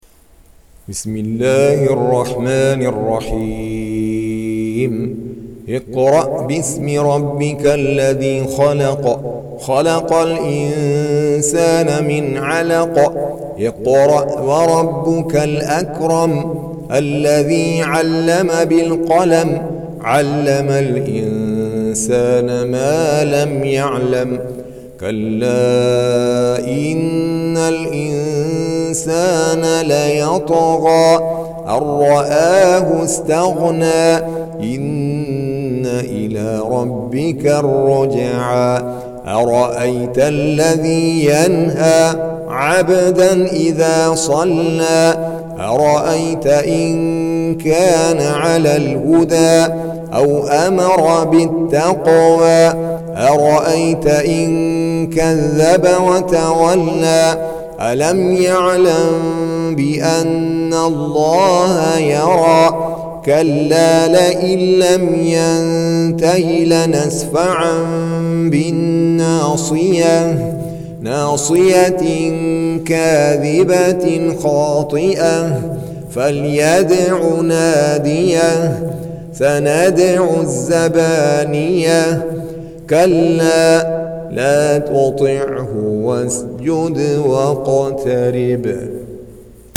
Tarteel Recitation
Surah Sequence تتابع السورة Download Surah حمّل السورة Reciting Murattalah Audio for 96. Surah Al-'Alaq سورة العلق N.B *Surah Includes Al-Basmalah Reciters Sequents تتابع التلاوات Reciters Repeats تكرار التلاوات